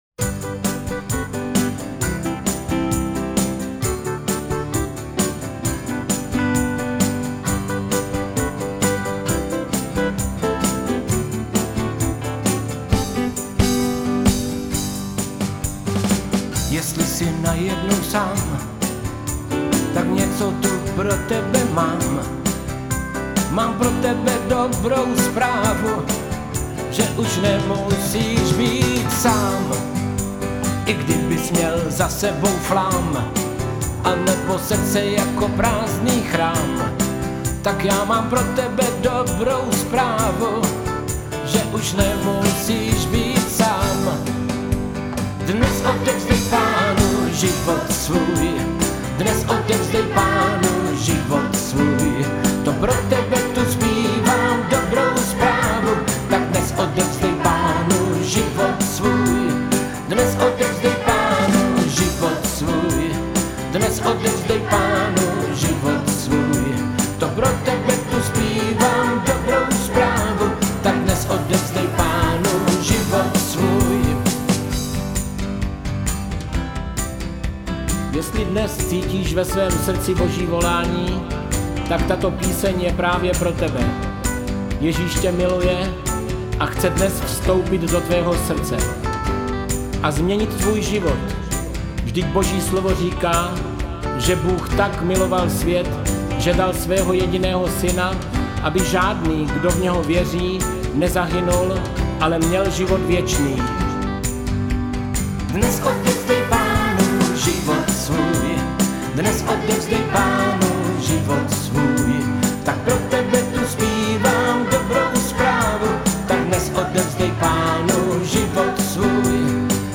Křesťanské písně
píseň s modlitbou spasení